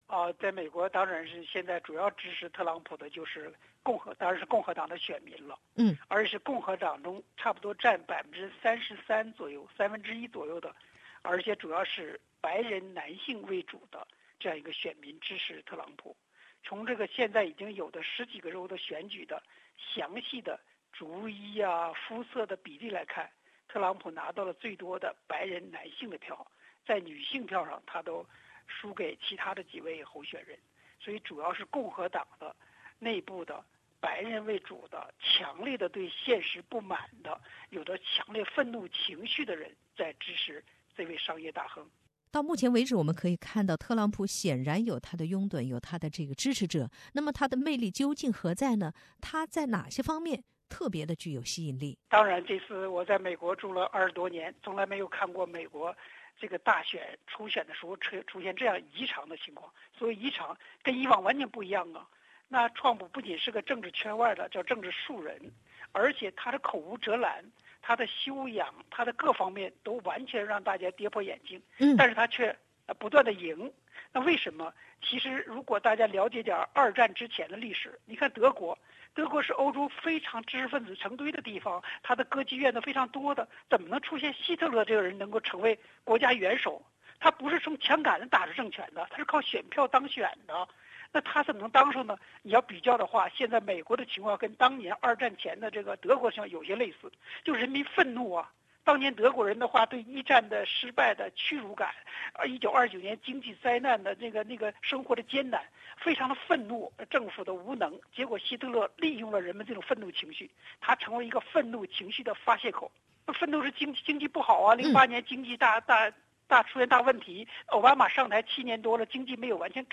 (嘉宾观点仅为个人意见，不代表本台立场) READ MORE 有看头：特朗普若成总统候选人 希拉里个人魅力将与其争锋 特朗普和希拉里成为"超级星期二"最大赢家 分享